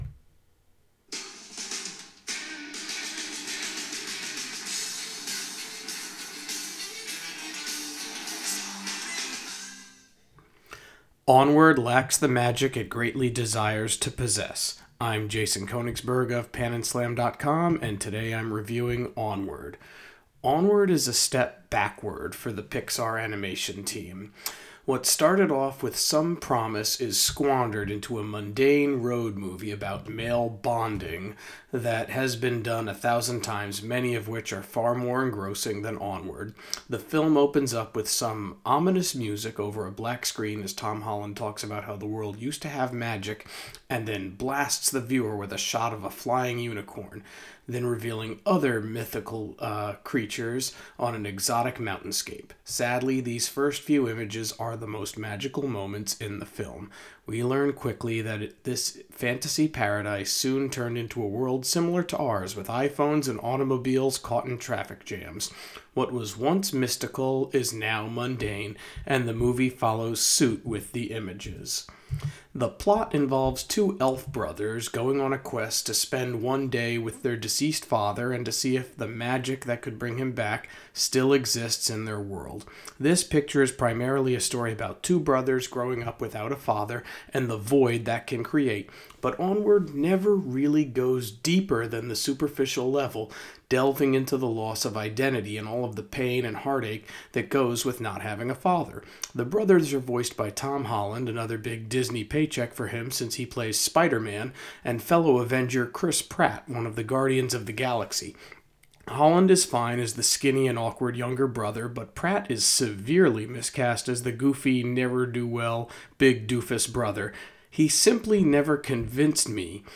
Movie Review: Onward